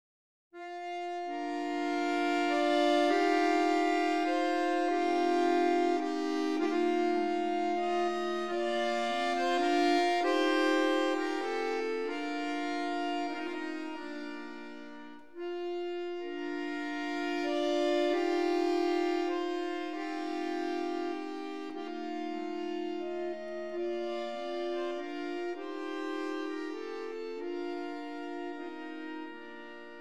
Norwegian folk music